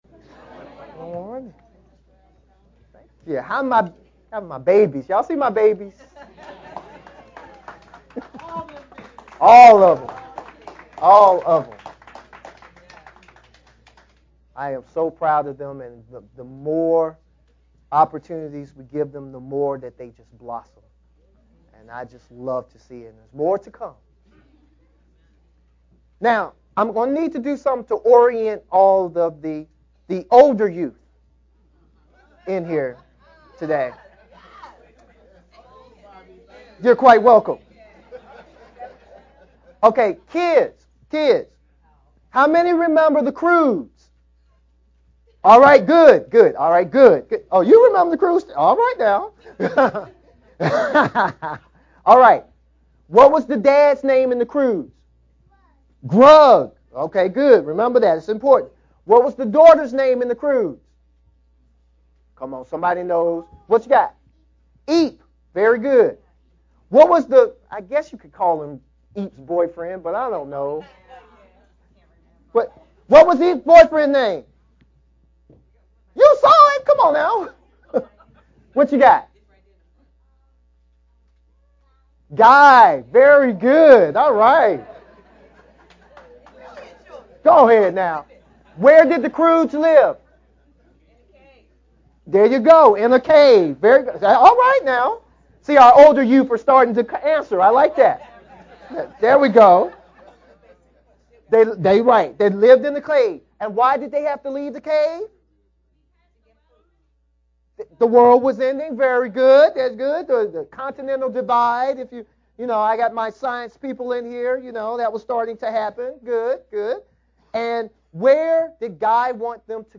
1 Kings 19: 1-18 A partnership with Christ assures you of His control when you assume all is lost. Message